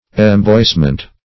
Search Result for " emboyssement" : The Collaborative International Dictionary of English v.0.48: Emboyssement \Em*boysse"ment\ ([e^]m*bois"ment), n. [See Embushment .]